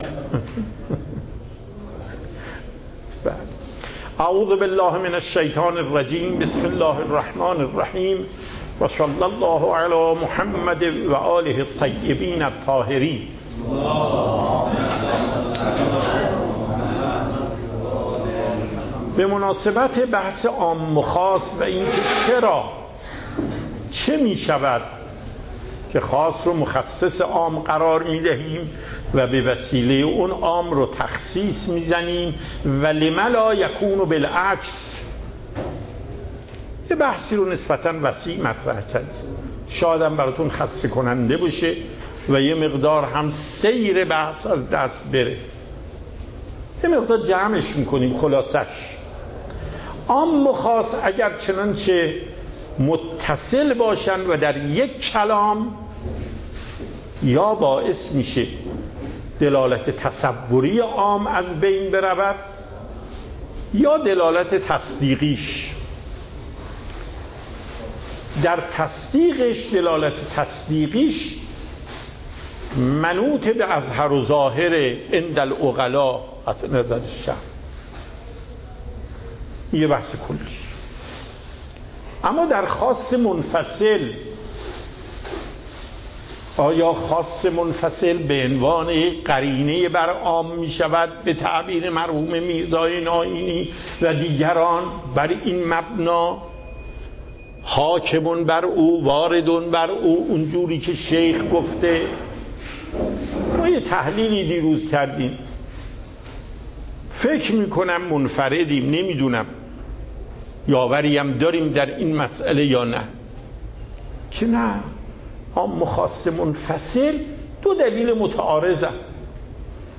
درس اصول آیت الله محقق داماد